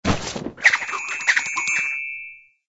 SA_pick_pocket.ogg